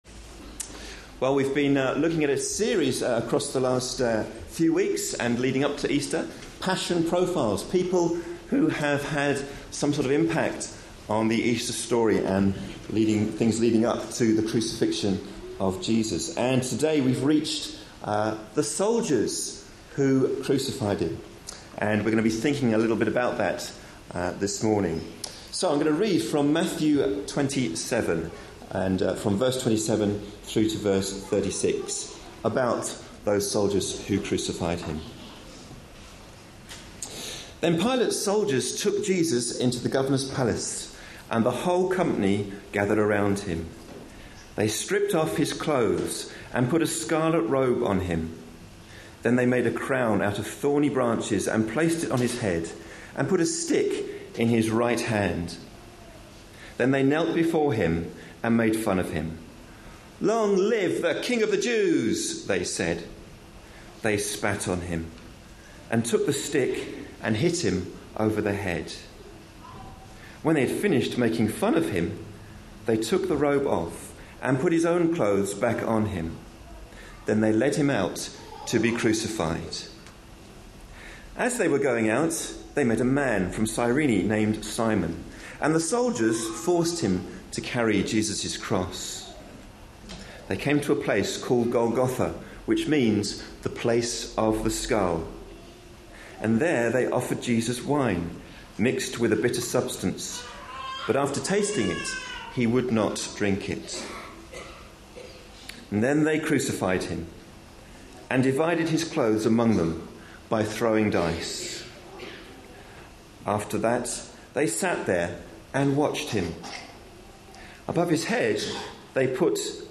A sermon preached on 17th March, 2013, as part of our Passion Profiles and Places -- Lent 2013. series.